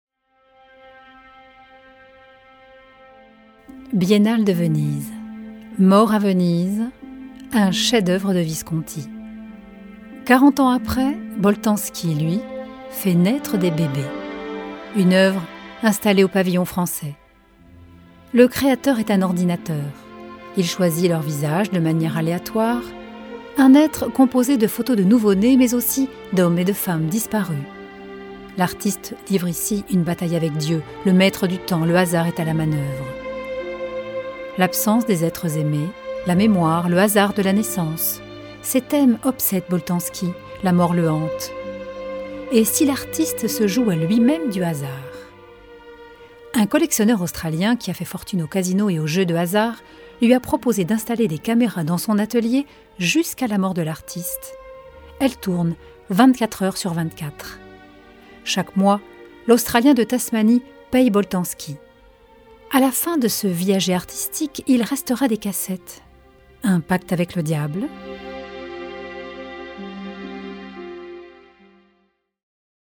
Comédienne et Comédienne Voix
Sprechprobe: Sonstiges (Muttersprache):